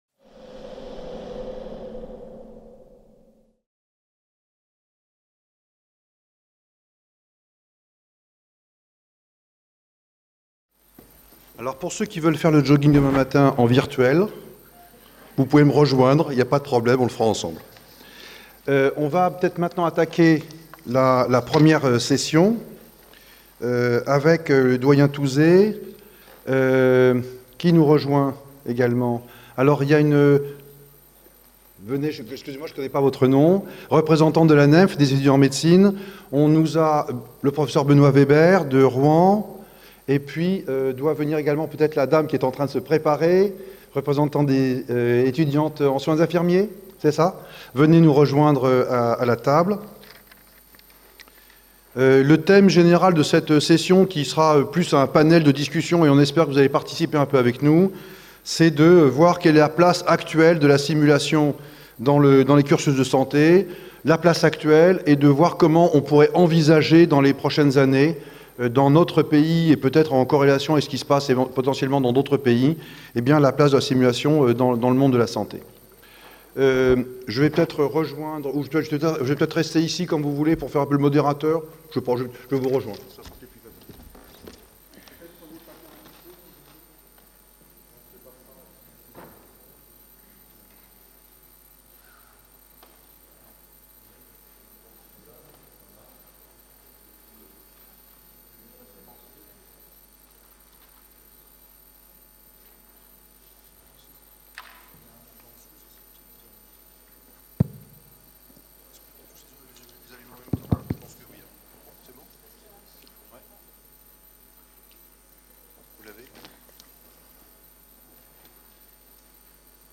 SOFRASIMS 2018 | 02 - Table ronde : Place de la Simulation dans les cursus en santé | Canal U